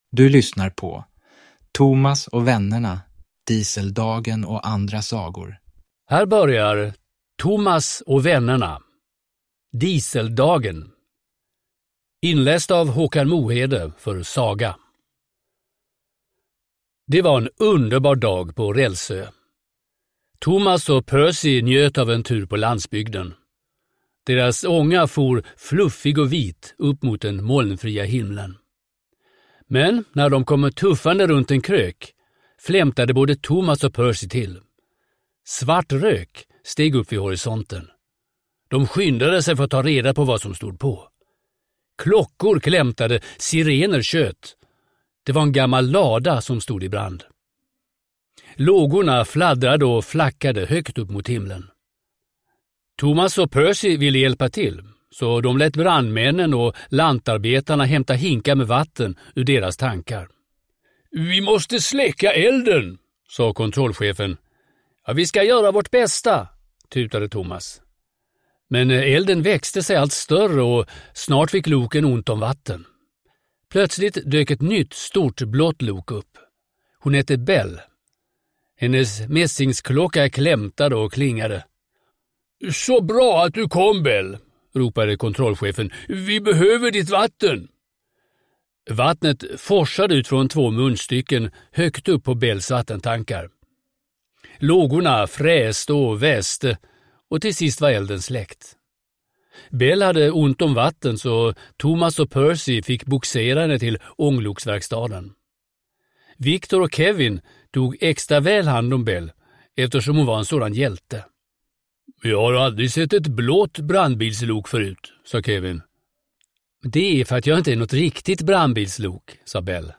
Thomas och vännerna – Dieseldagen och andra sagor – Ljudbok